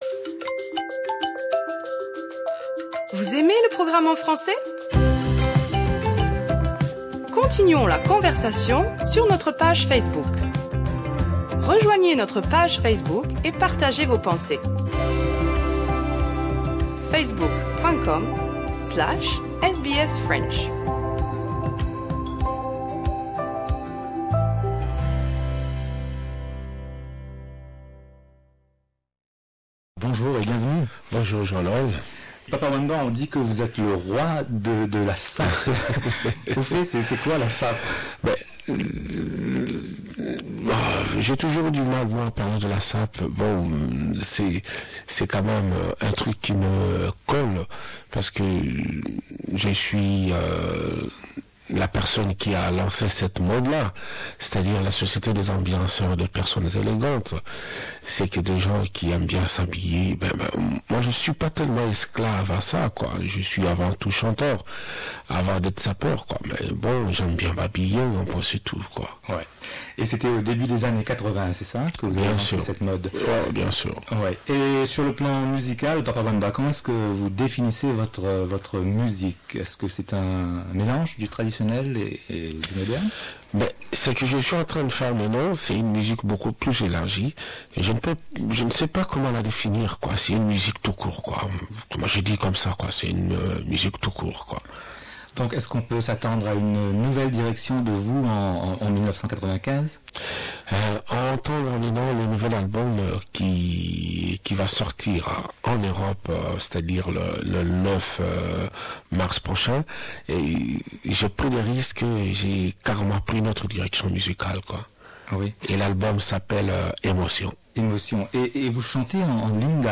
Papa Wemba dans nos studios...en 1995
En hommage a Papa Wemba, nous ouvrons nos archives, l'annee.. 1995 ... il etait venu dans nos studios...